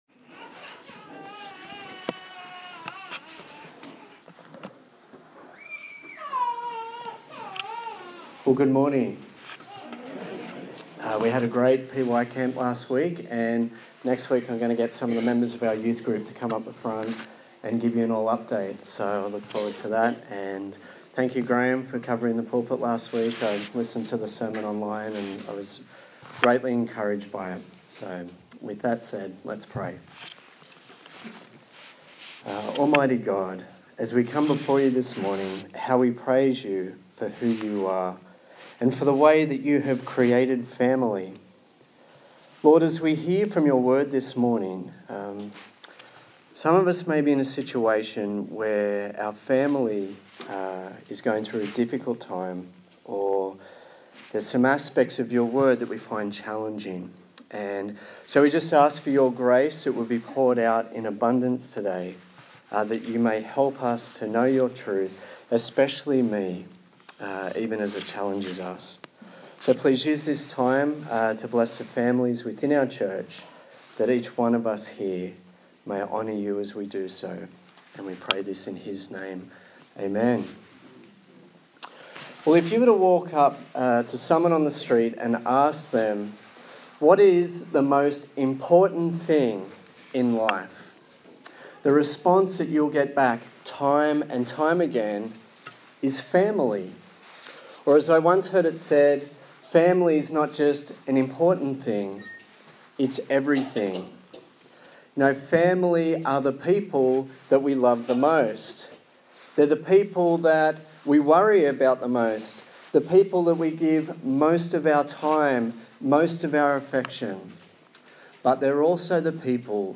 Colossians Passage: Colossians 3:18-21 Service Type: Sunday Morning